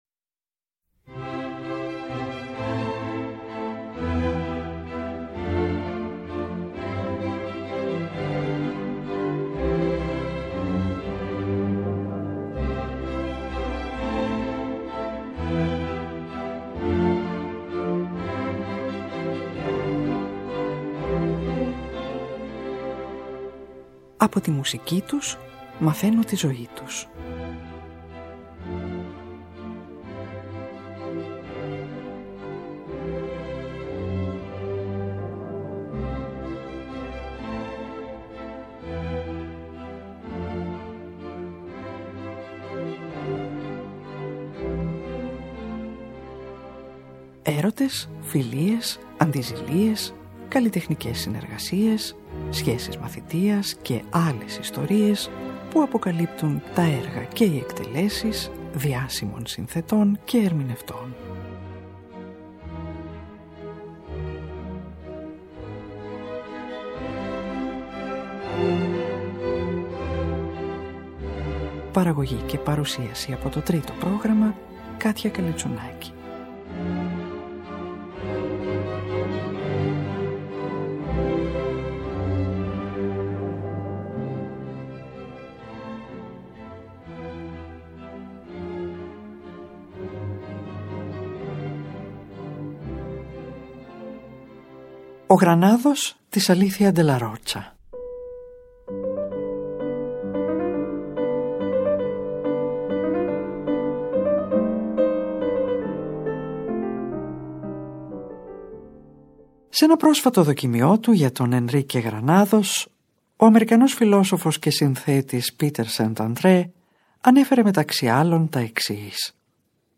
Από ηχογραφήσεις της περιόδου 1974-1985
soprano